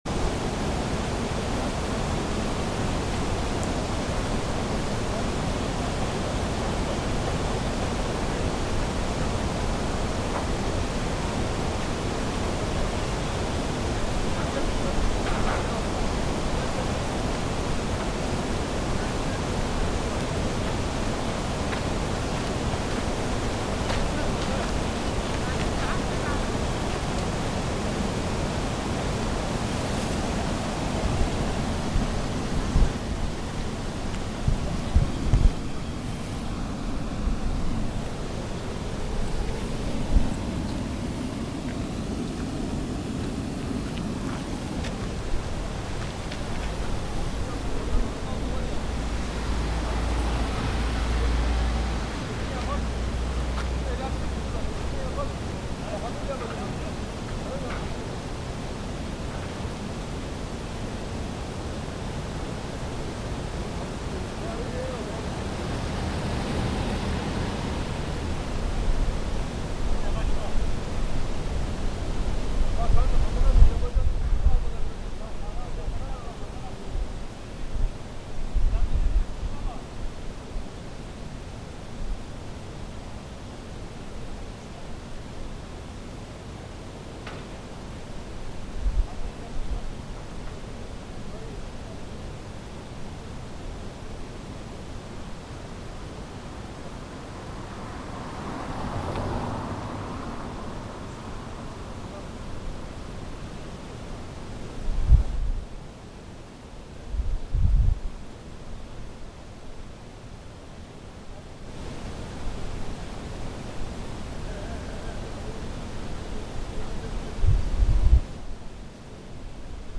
Waterfall at Uzungöl
This field recording was taken at Uzungöl, one of the most popular touristic place in Trabzon. Although the general characteristics of the soundscape of this lake mostly has the anthrophonic features, the eastern part of the lake where this recording has been taken has less human circulation and activities. Thus, at this place there is an artificial waterfall where the river goes towards the lake. The most interesting thing of this recording is the buzzing sound of the waterfall. This recording has been taken by Zoom H1n sound recorder.